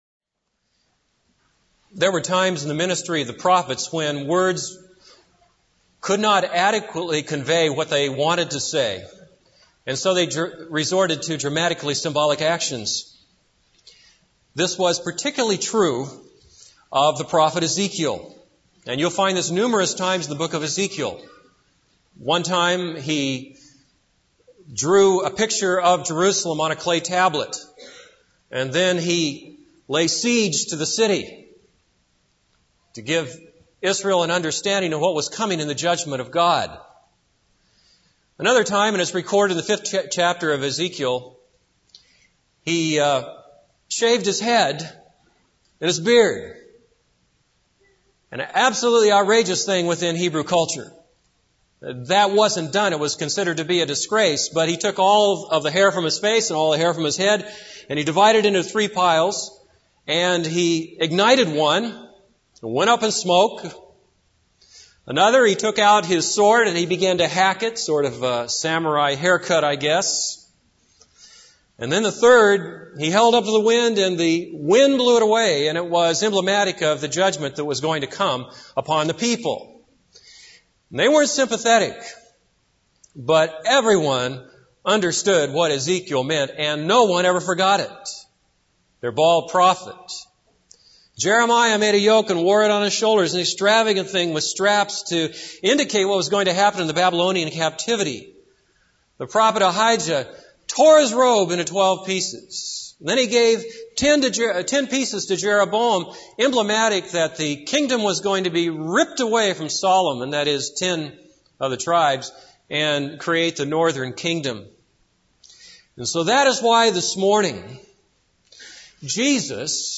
This is a sermon on Mark 14:12-26.